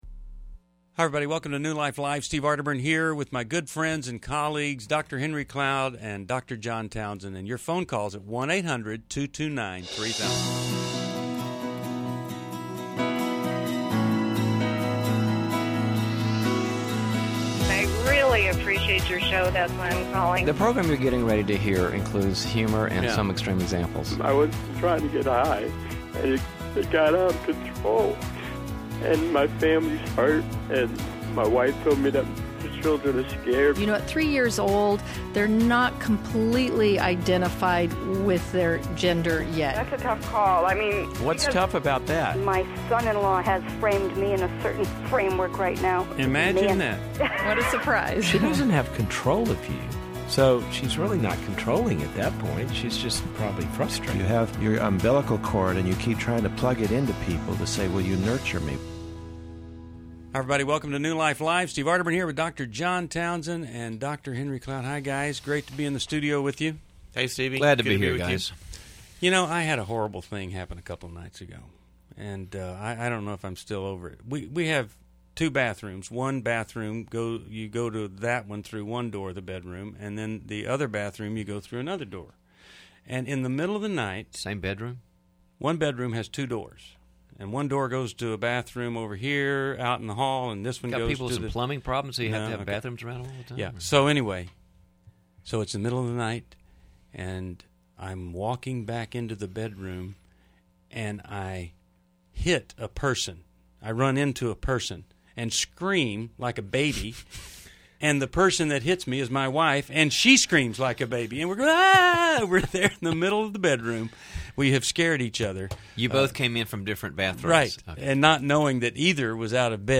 Caller Questions: 1. How do I grieve my husband turning his back on God? 2. Should I turn in a slanderer? 3.